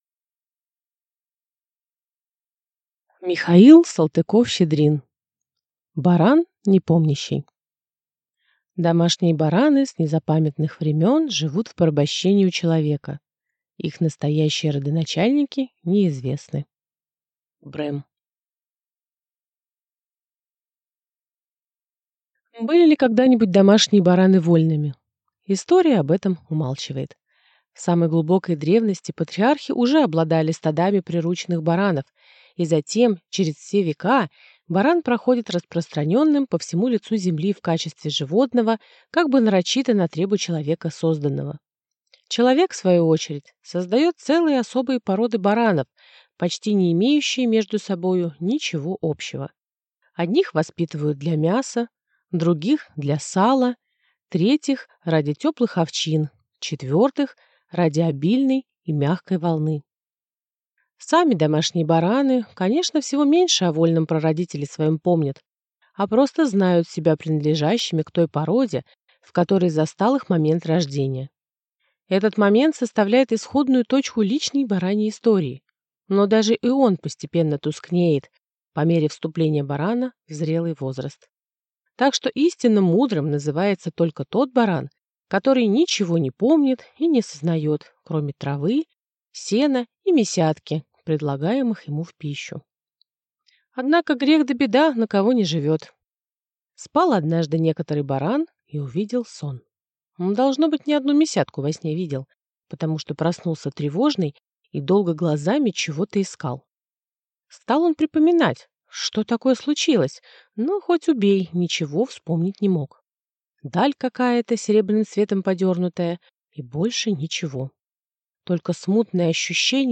Аудиокнига Баран-непомнящий | Библиотека аудиокниг